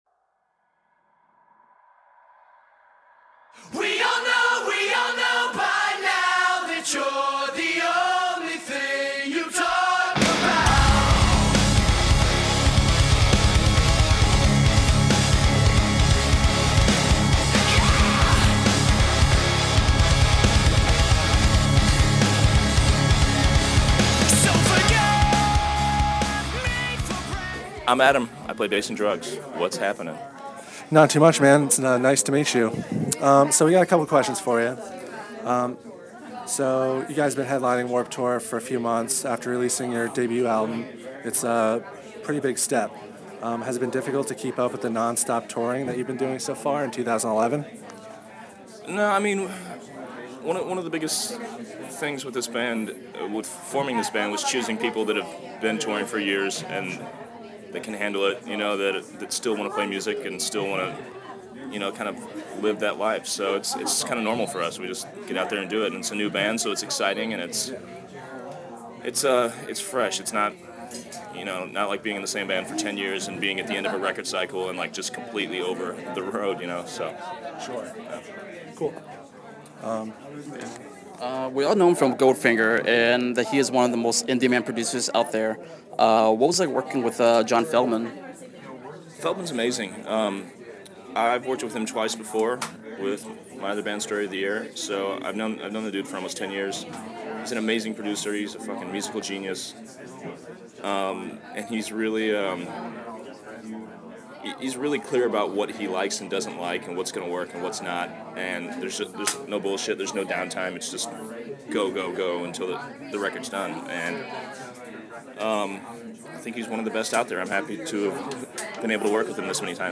Exclusive: D.R.U.G.S. Interview
02-interview-d-r-u-g-s.mp3